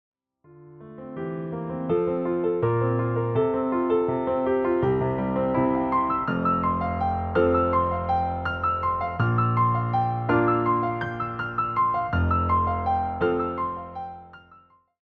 bigger, more powerful moments